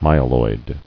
[my·e·loid]